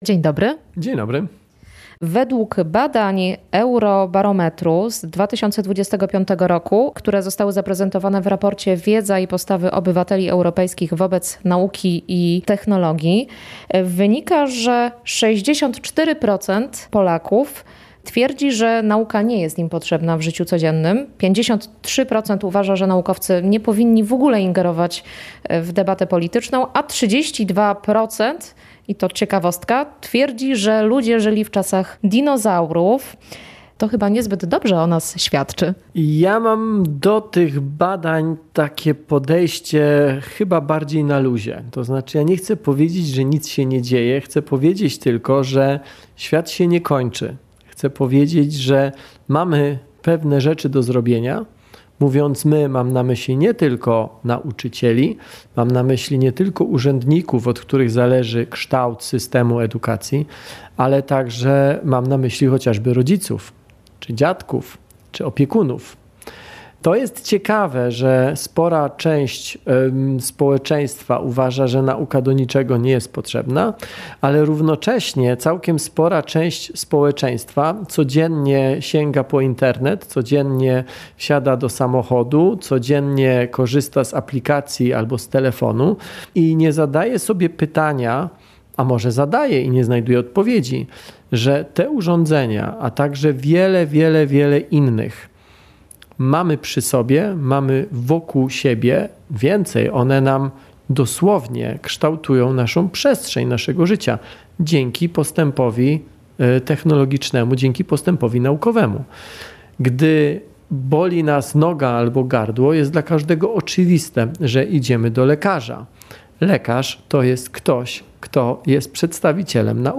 Jednocześnie, jak podkreślił w rozmowie z Polskim Radiem Rzeszów dr Tomasz Rożek, fizyk i założyciel Fundacji "Nauka to Lubię", korzystamy z jej osiągnięć niemal na każdym kroku, od Internetu po medycynę.